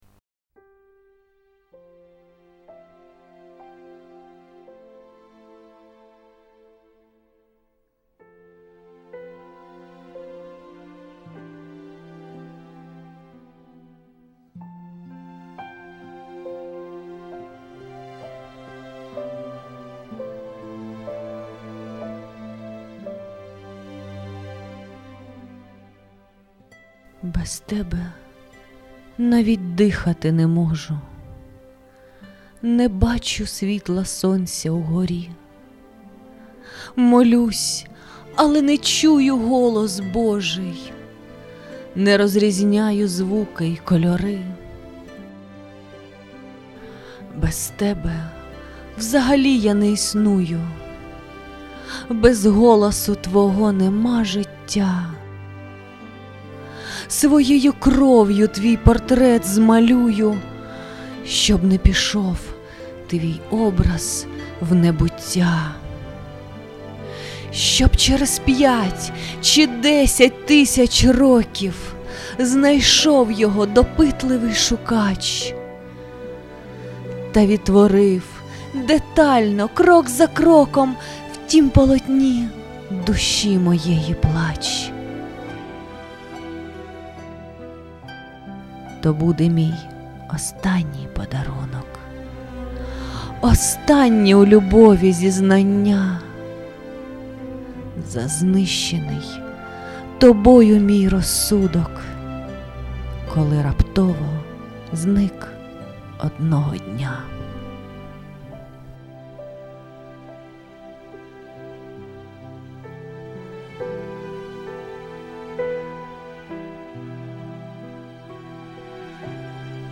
Текст читає автор